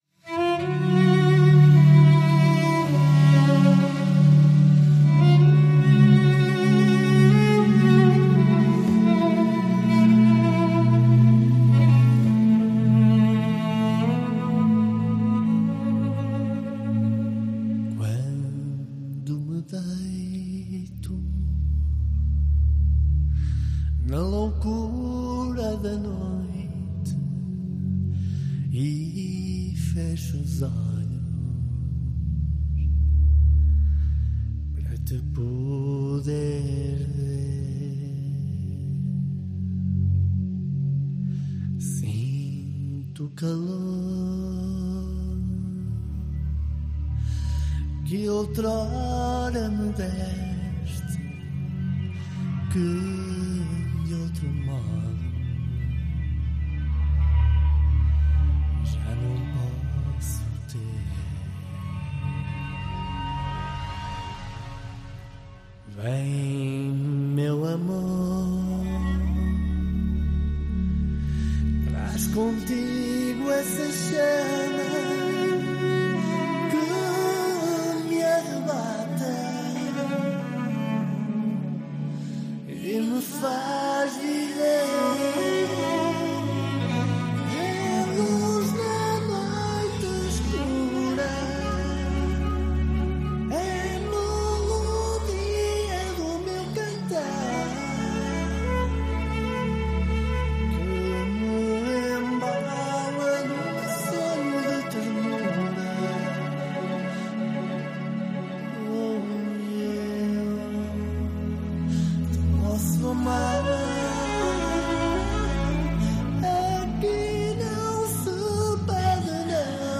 vocals
vocals/instrumental